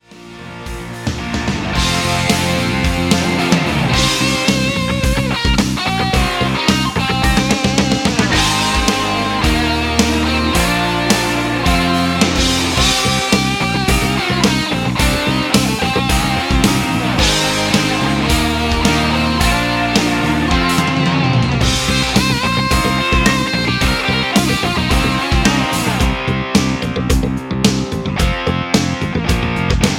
Backing track Karaoke
Rock, Oldies, 1960s